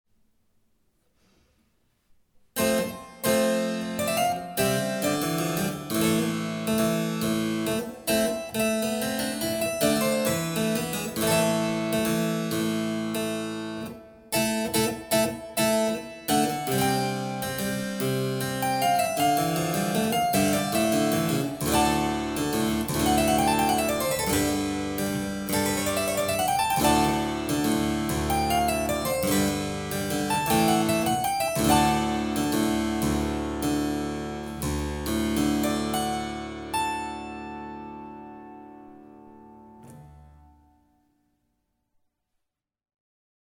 Clavicembalo
CLAVICEMBALO-MarchetaSaporita.mp3